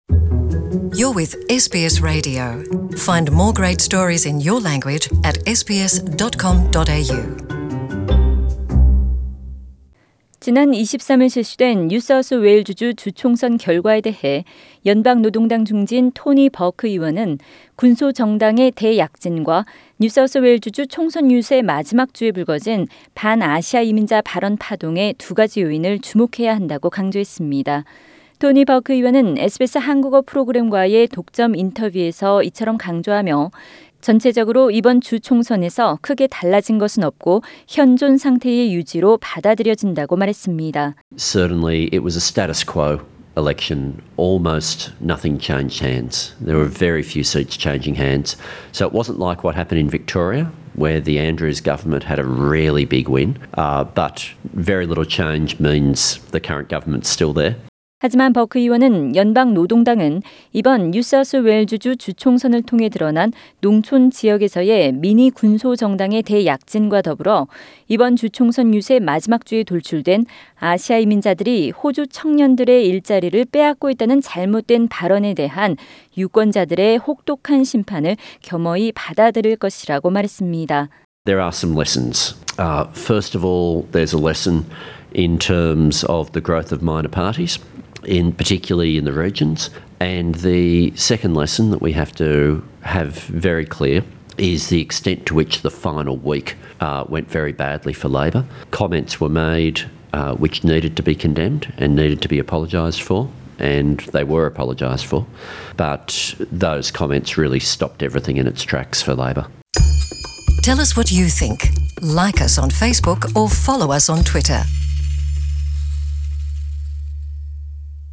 SBS Korean Program has exclusively conducted an interview with Labor frontbencher Tony Burke after the NSW election.